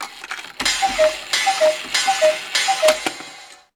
F2939_alarm.wav